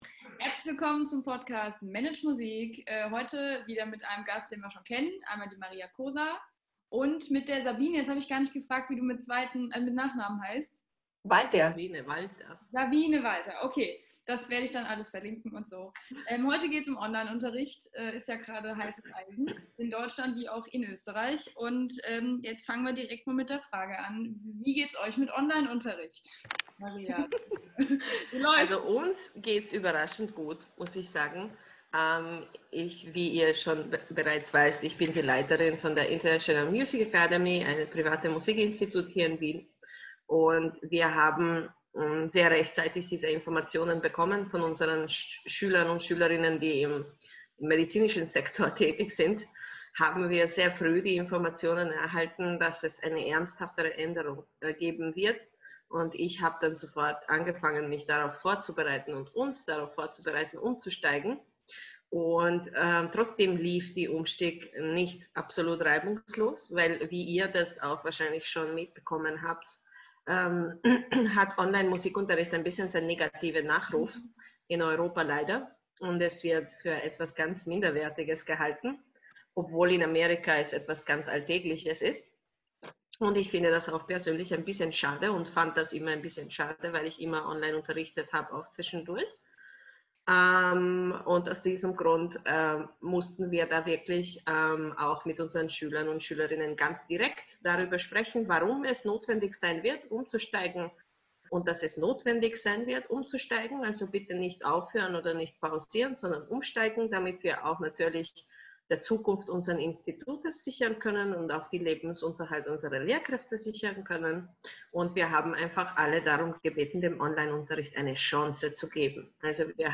interview-zum-thema-online-unterricht-mmp.mp3